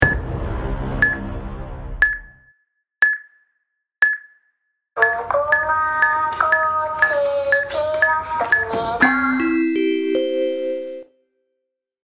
10 Second Squid Game Timer Sound Button - Free Download & Play